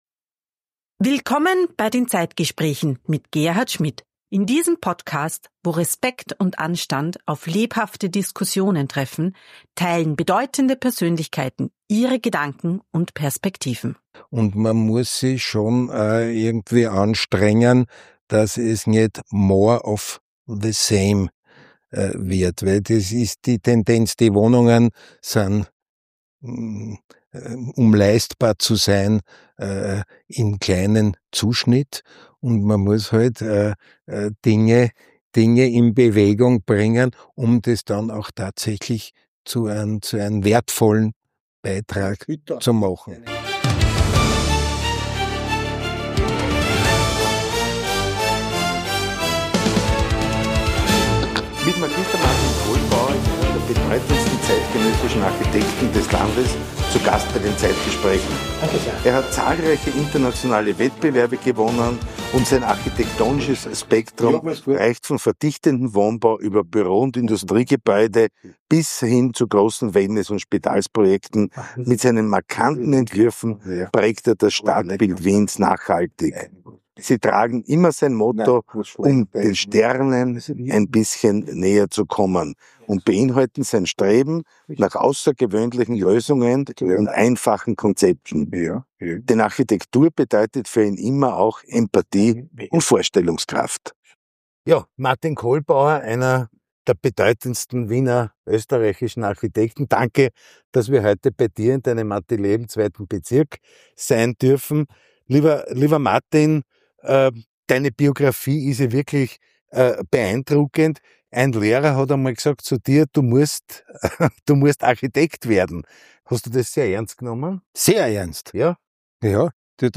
Zeit für Gespräche – Zeit für Antworten.